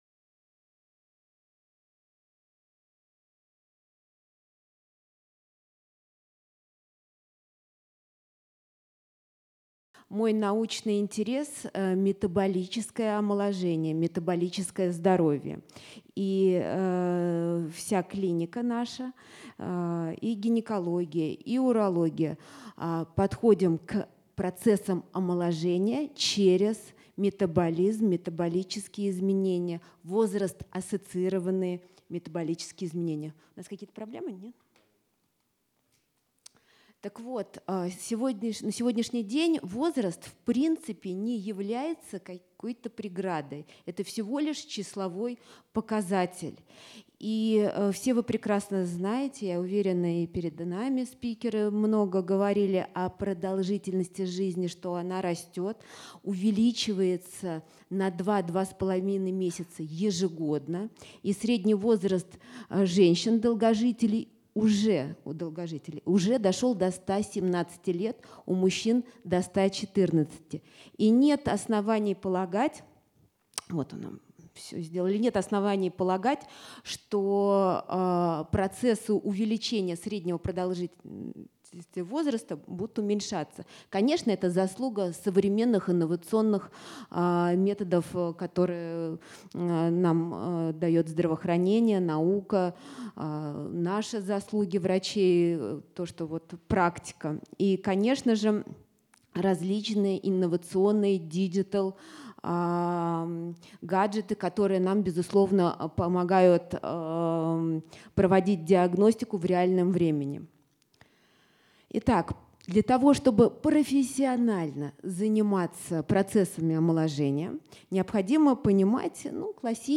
Метаболическое омоложение Дата: 15.02.2025 Источник: Международная конференция "Дерево жизни".